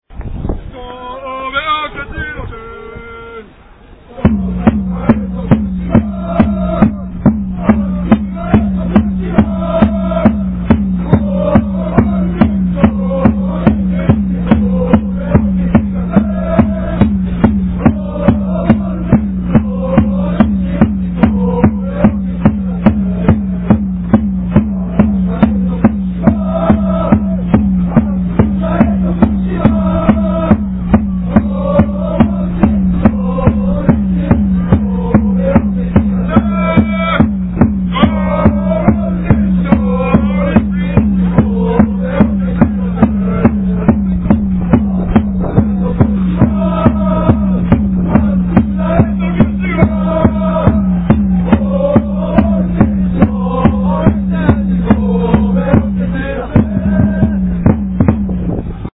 チームのチャント